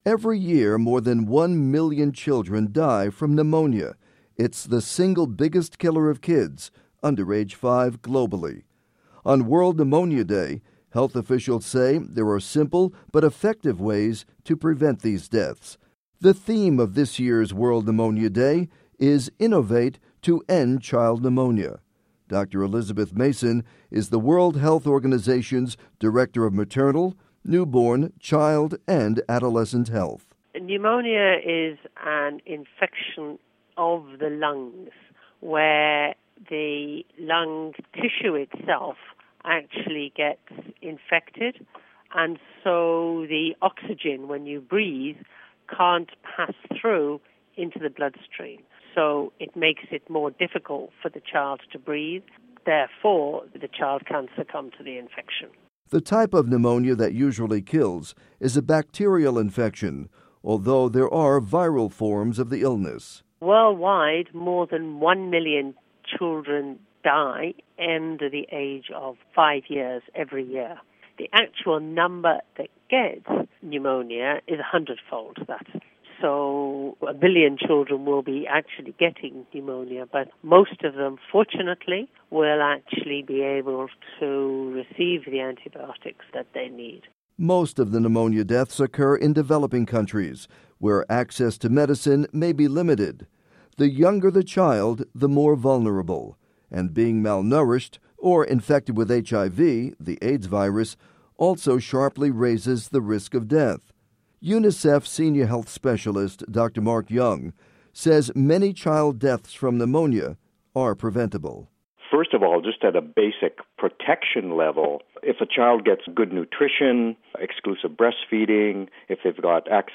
report on World Pneumonia Day